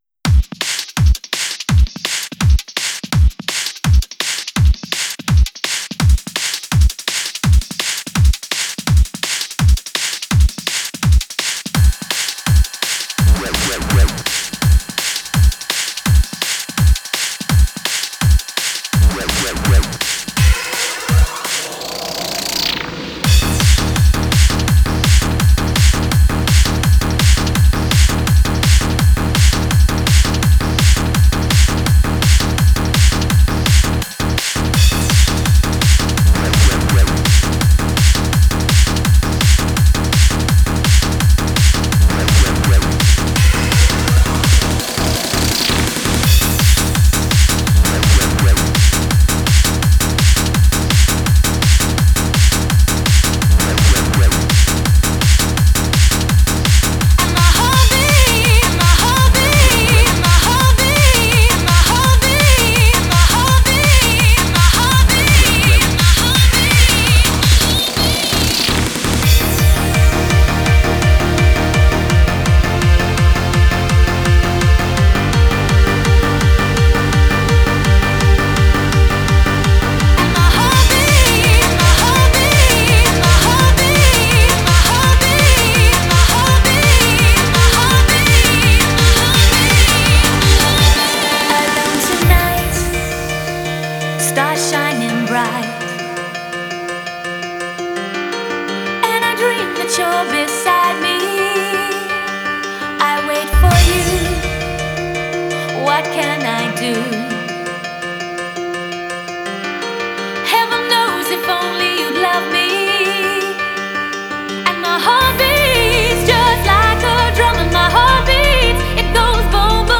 Hardcore Mix